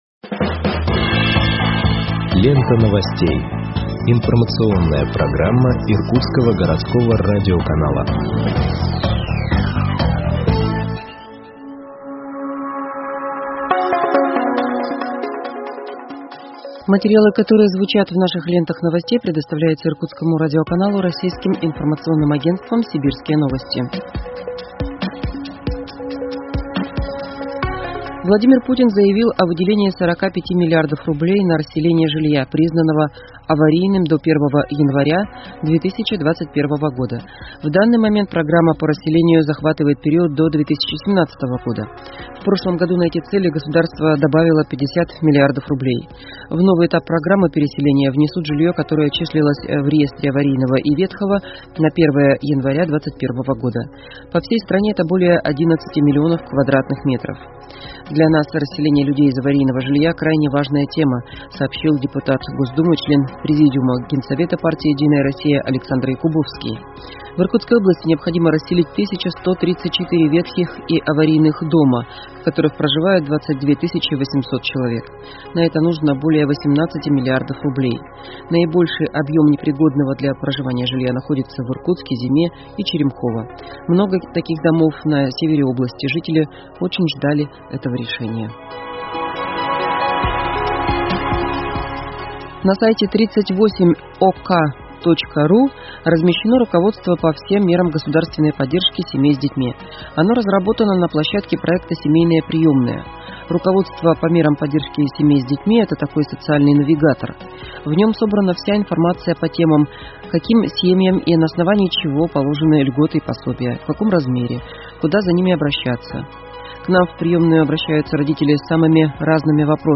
Выпуск новостей в подкастах газеты Иркутск от 26.08.2021 № 2